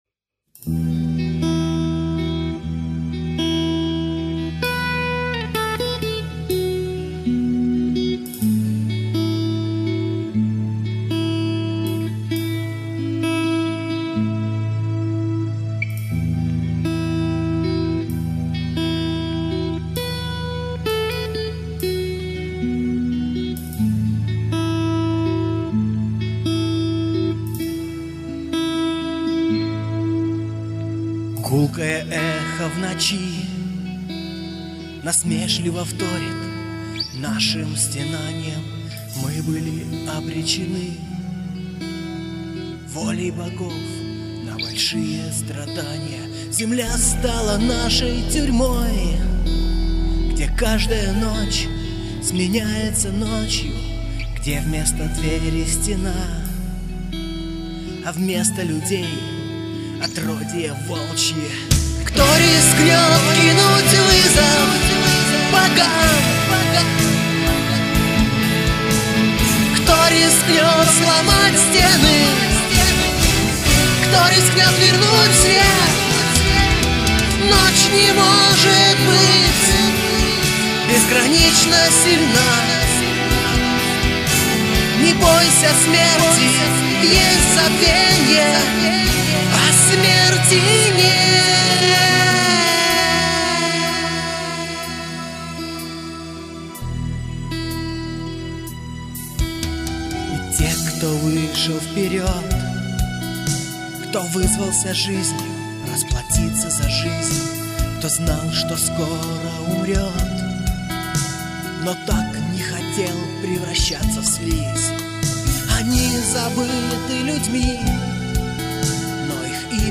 Гитары, клавиши, перкуссия, вокал